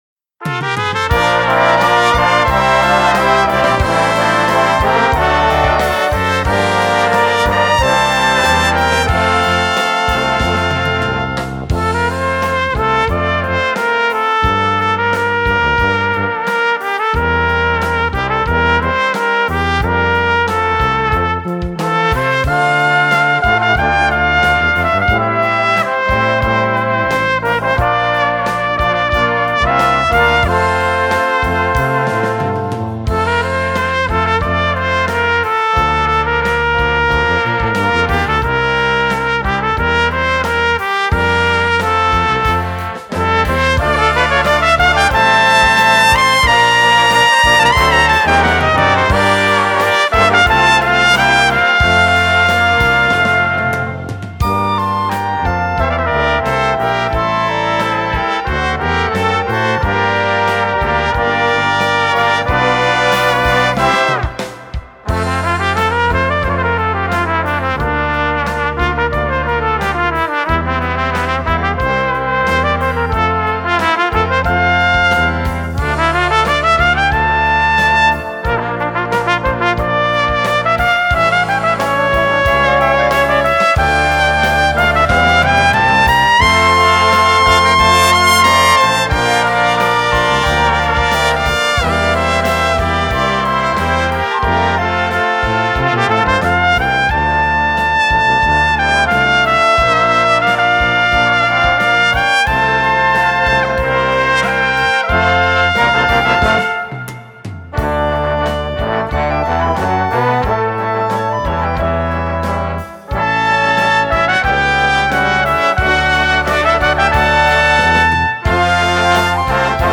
Dychová hudba
Inštrumentalne
Slow , Sólovky trumpeta